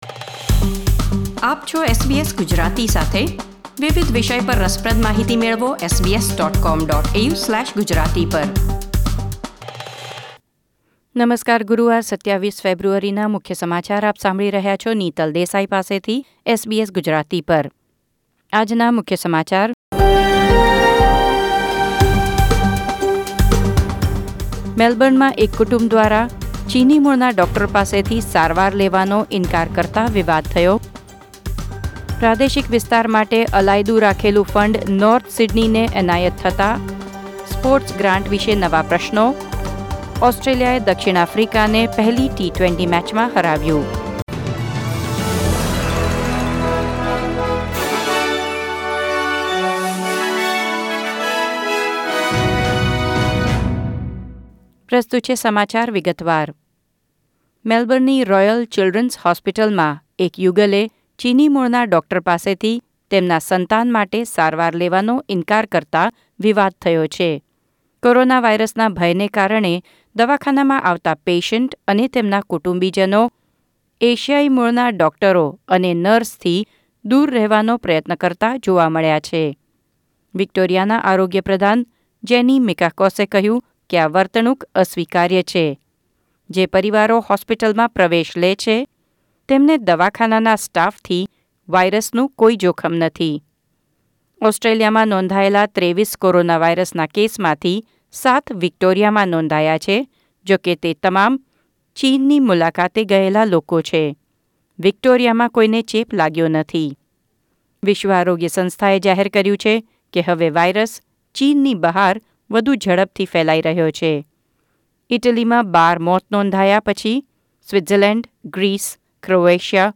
SBS Gujarati News Bulletin 27 February 2020